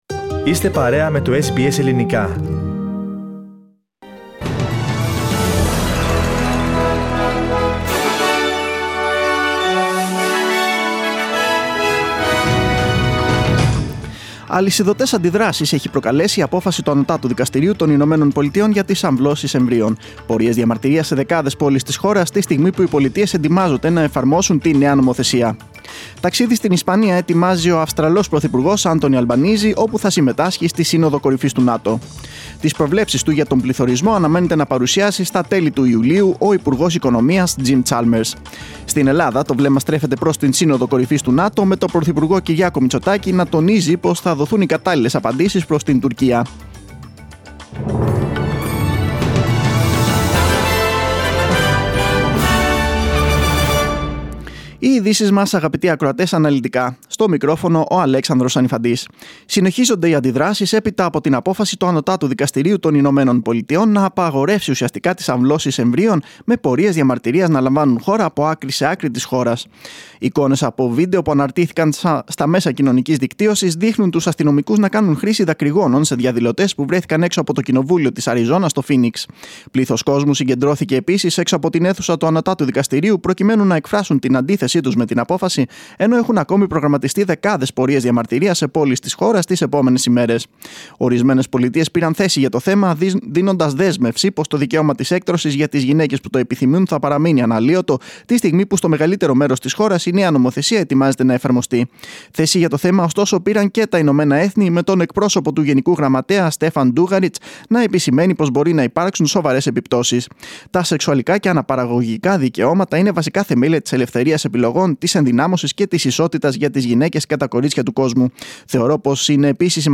Δελτίο Ειδήσεων 26.6.2022
News in Greek. Source: SBS Radio